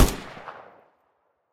minigun_far.ogg